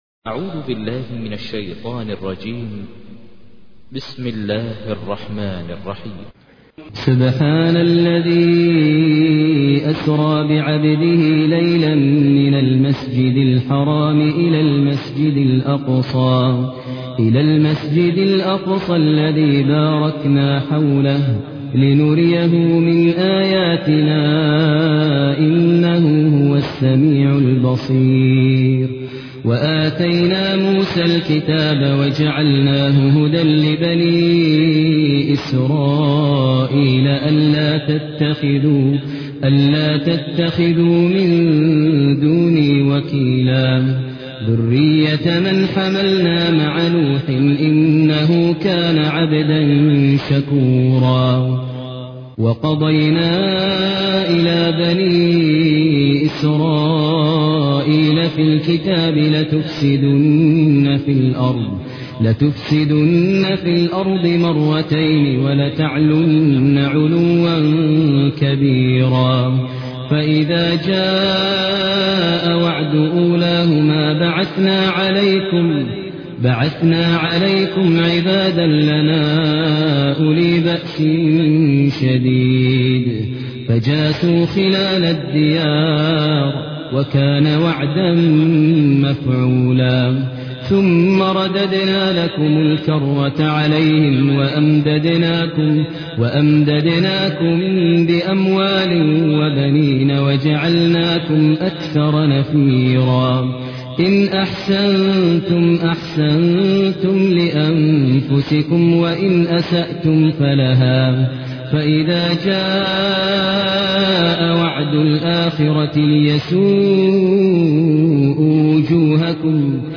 تحميل : 17. سورة الإسراء / القارئ ماهر المعيقلي / القرآن الكريم / موقع يا حسين